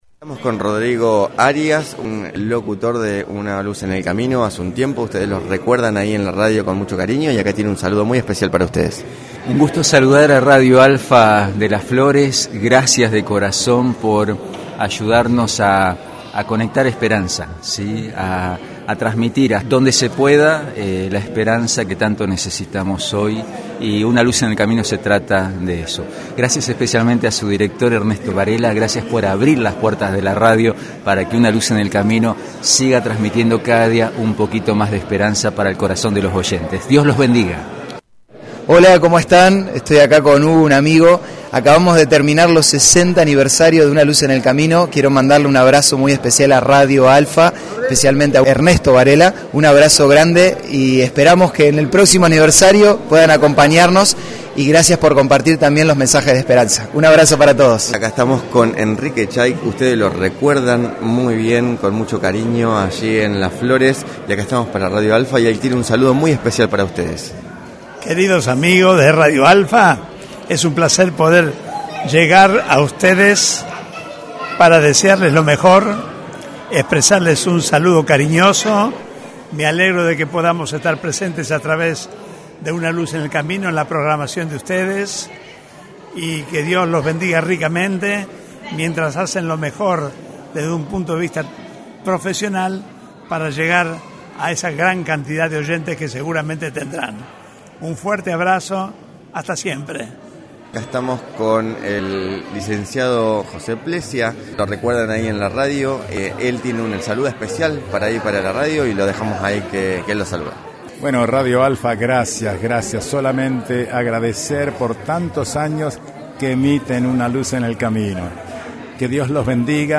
una-luz-en-el-camino-saludos.mp3